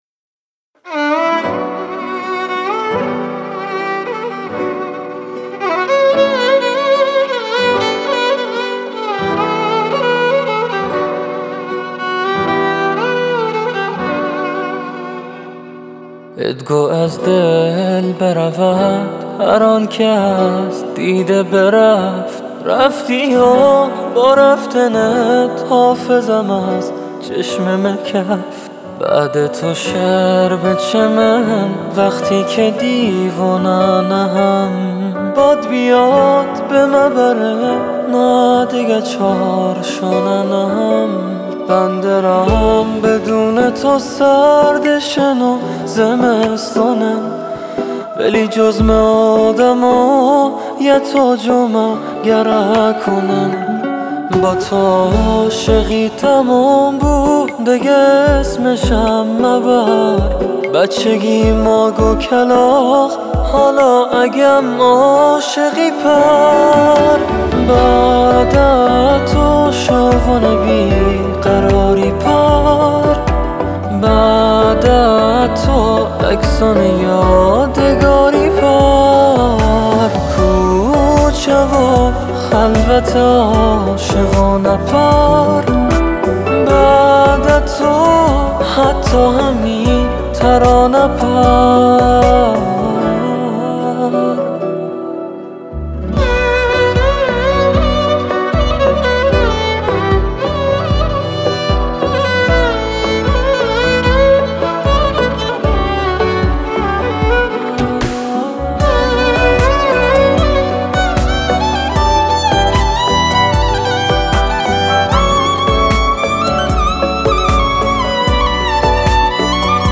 آهنگ بندری